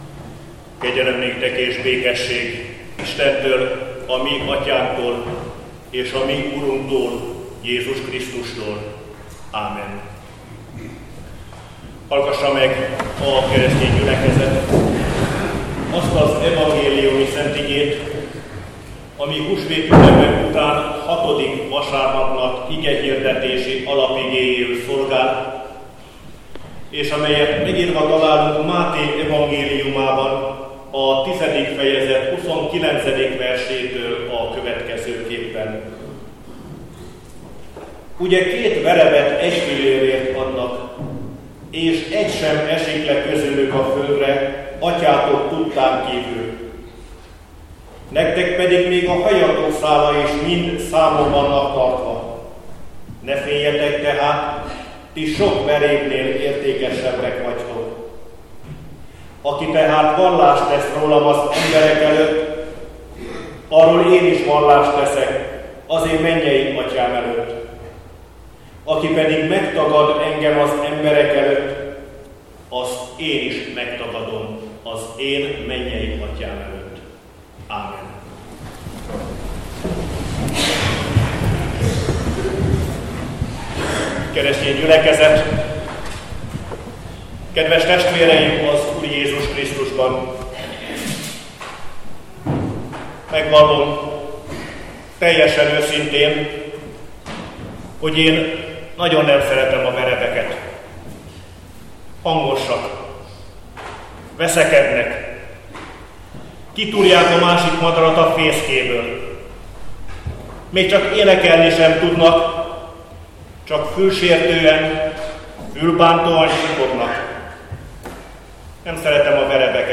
Igehirdetések